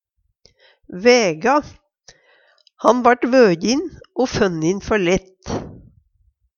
væga - Numedalsmål (en-US)
DIALEKTORD PÅ NORMERT NORSK væga vege, finne ut vekta Infinitiv Presens Preteritum Perfektum væga væg vog vøje Eksempel på bruk Han vart vøjin o funnin før lett.